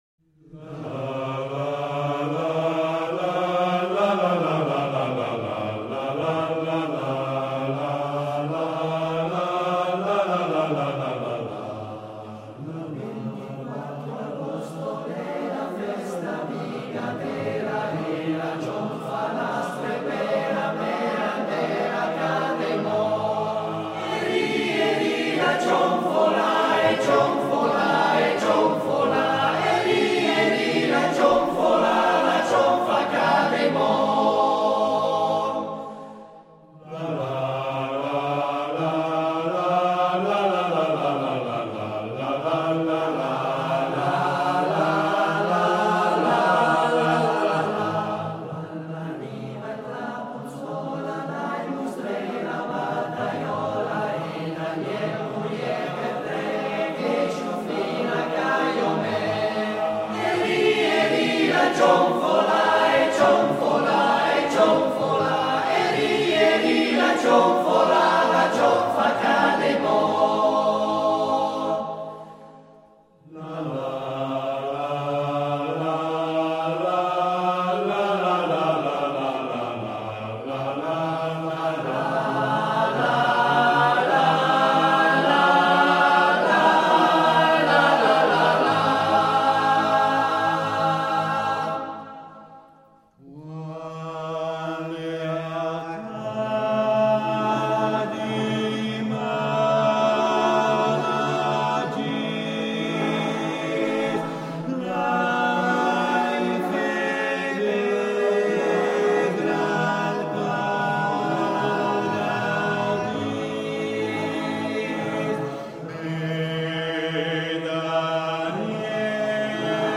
Arrangiatore: Vacchi, Giorgio (Armonizzatore) Esecutore: Coro CAI Sondrio